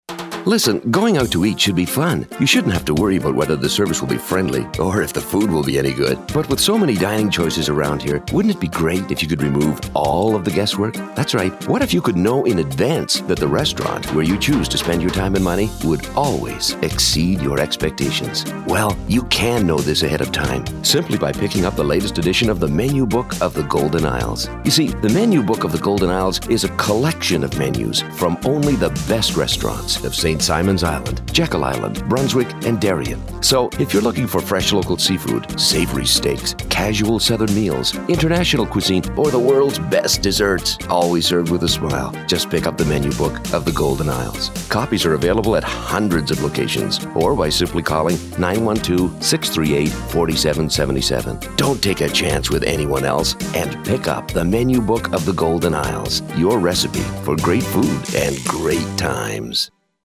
Experienced professional voiceovers, Canadian, American, guaranteed, free auditions
Sprechprobe: Sonstiges (Muttersprache):